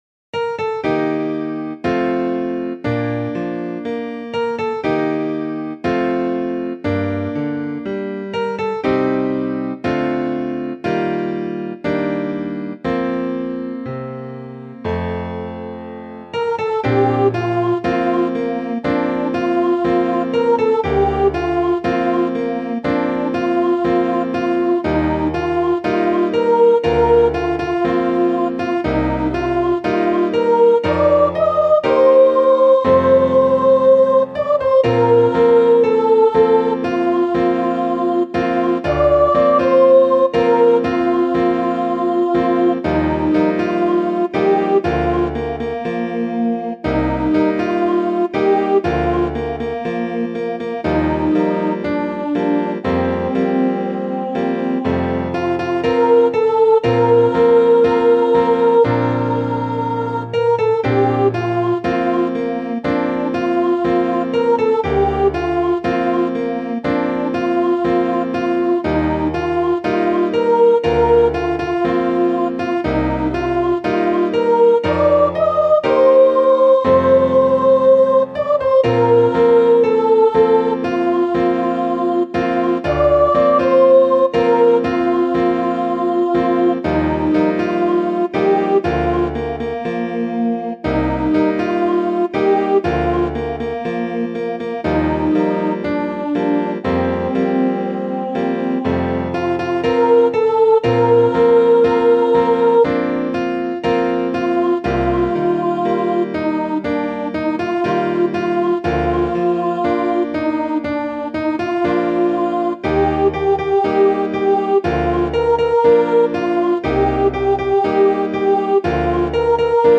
Voicing/Instrumentation: Duet